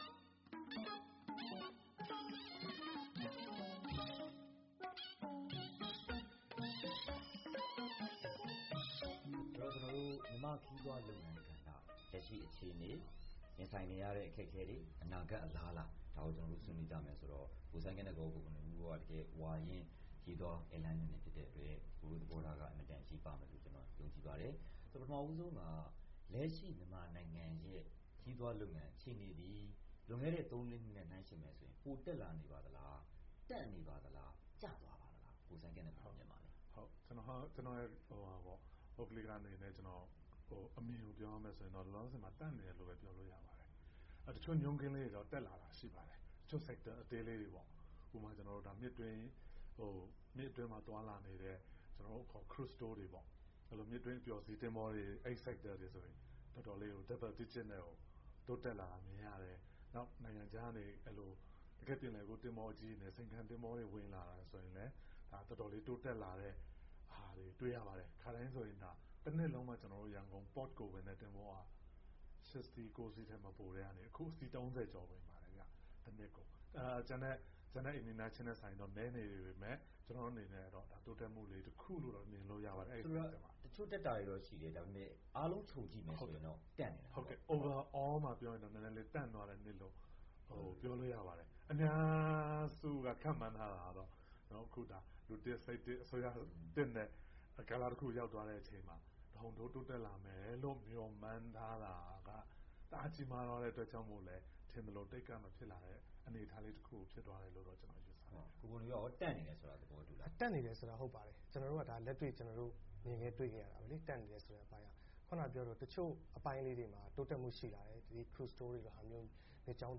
ရန်ကုန်မြို့ VOA Studio မှာ
တွေ့ဆုံမေးမြန်းထားပါတယ်။